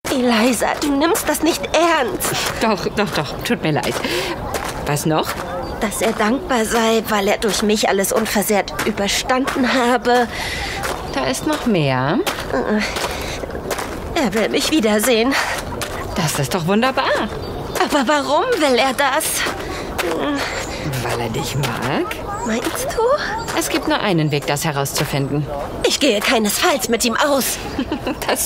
Werbung - Lexus